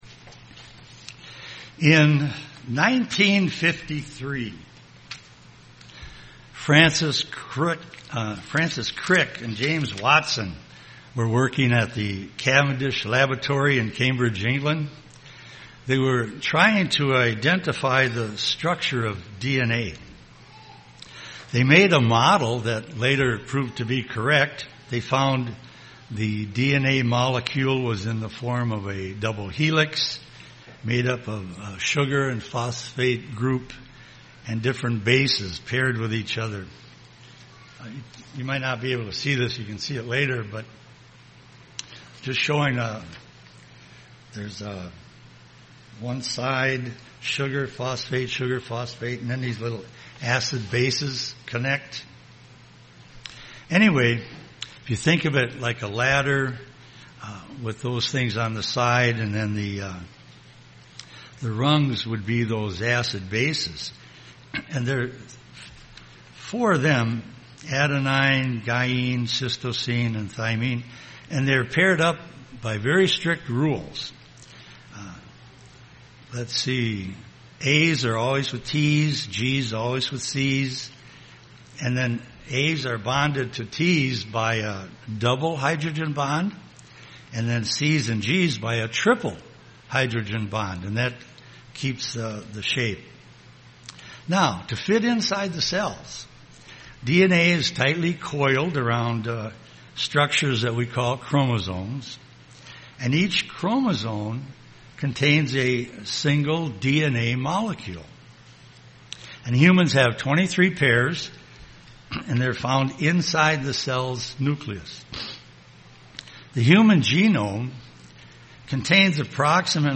Given in Twin Cities, MN
UCG Sermon human reasoning Studying the bible?